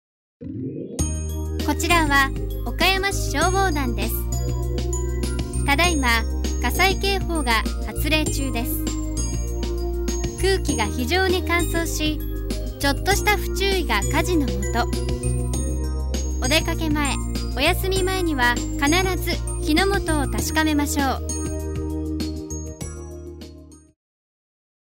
消防団車両等での巡回広報用音声メッセージを作成しました。内容別にそれぞれ男性と女性の声で収録しています。